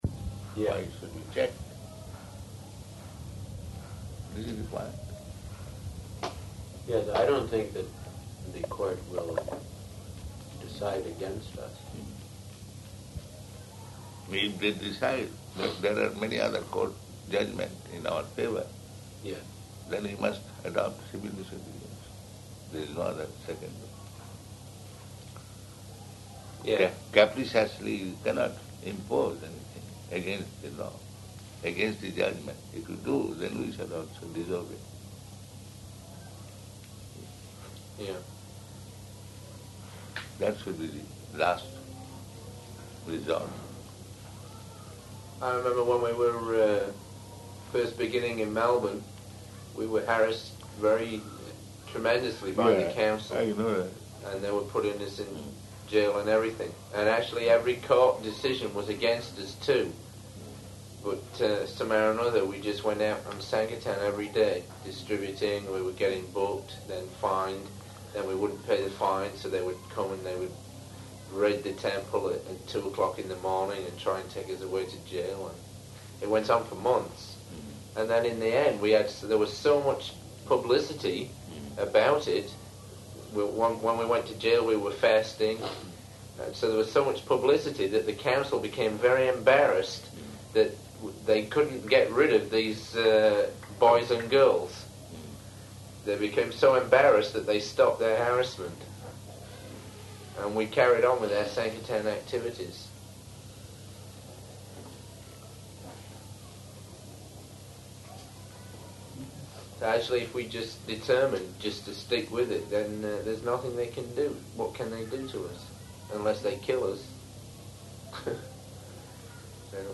Room Conversation